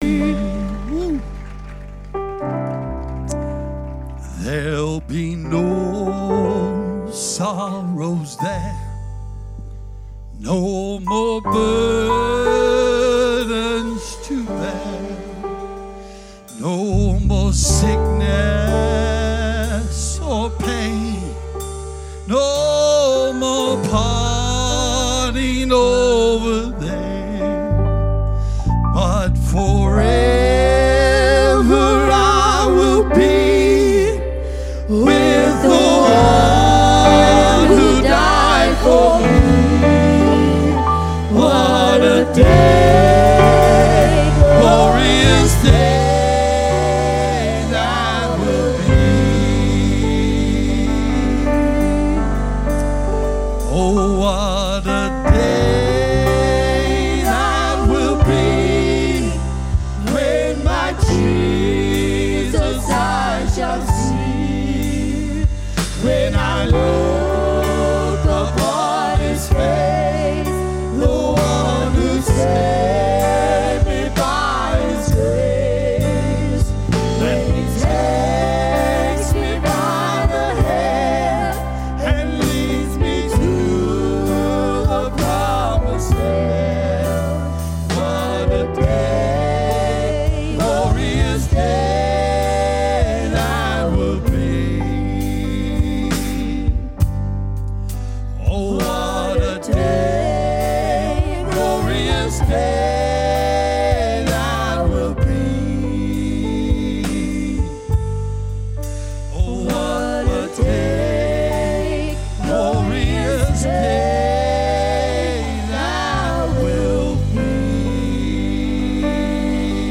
Sermons by Breaking Bread Apostolic Church